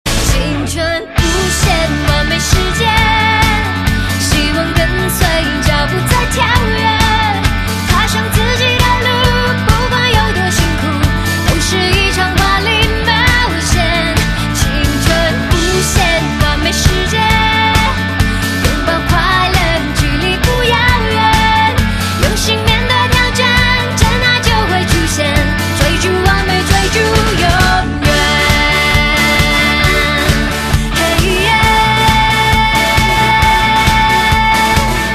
M4R铃声, MP3铃声, 华语歌曲 40 首发日期：2018-05-15 17:46 星期二